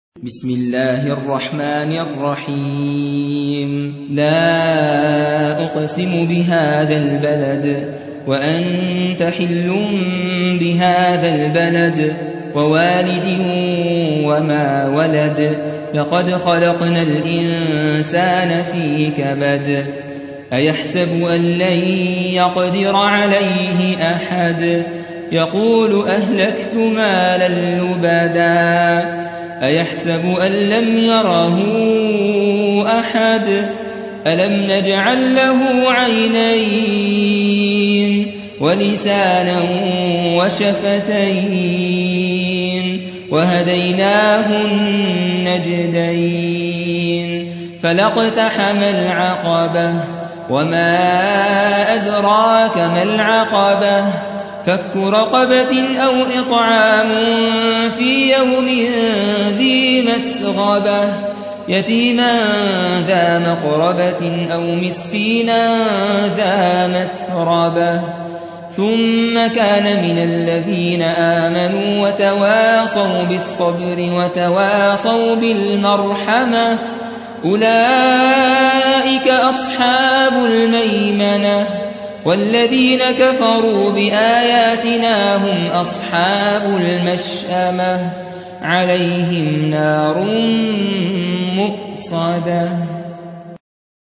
90. سورة البلد / القارئ